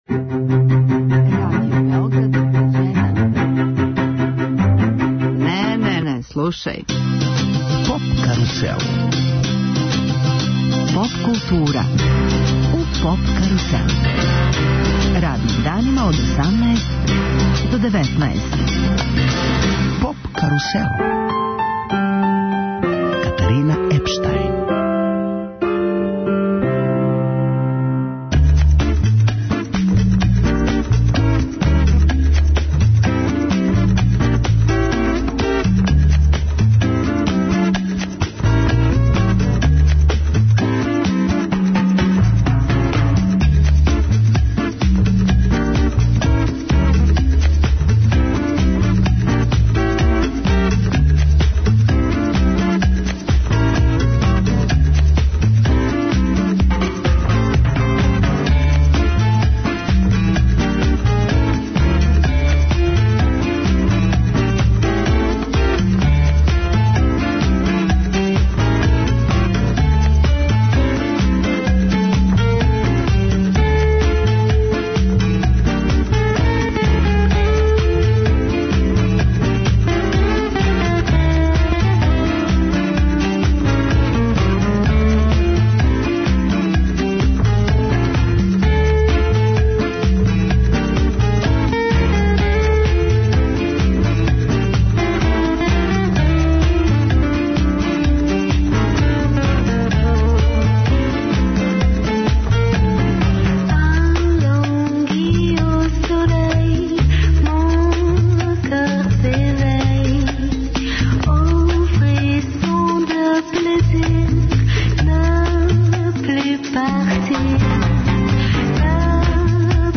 Македонска музичка група Љубојна одржаће концерт 23. фебруара у Дому синдиката. Данас свирају у нашој емисији.
Љубојна је македонски састав, који у свом репертоару има најстарије македонске песме обогаћене џез, брас, поп, соул и електронском музиком.